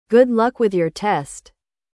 • /ʌ/ (O “Â” de boca fechada e estômago embrulhado)
É um som curto, central e relaxado.